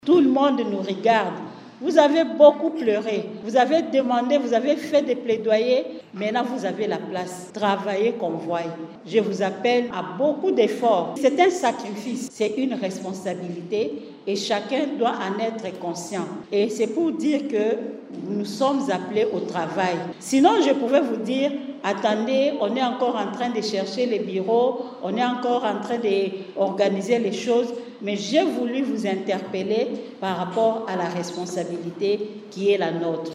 Cette rencontre, organisée dans la salle de réunion de la MONUSCO, a marqué un moment fort d’échange entre la ministre et les agents publics nouvellement affectés.